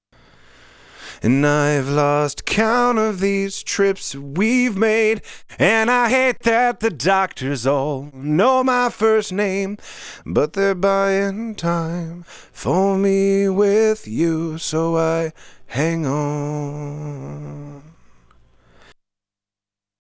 1176+La2a auf Vocals Please
hier nochmal die variante mit den freien plugins